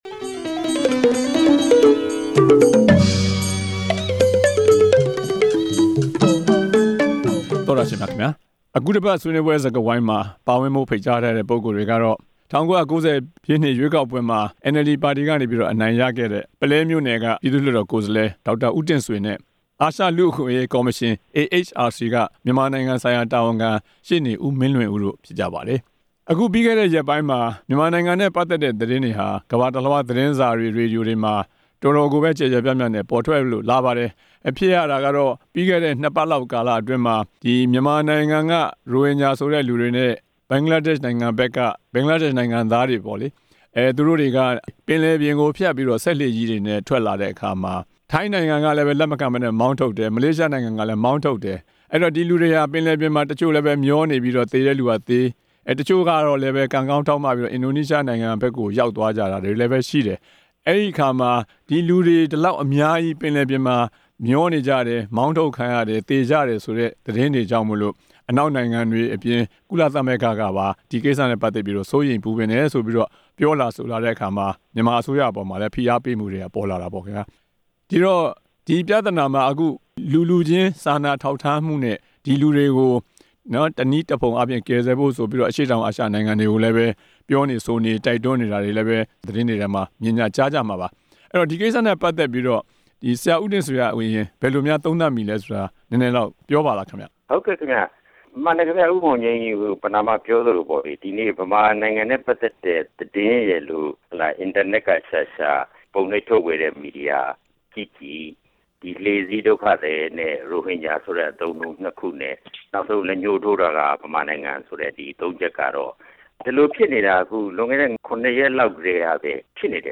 လှေစီးဒုက္ခသည်များ အရေး ဆွေးနွေးချက်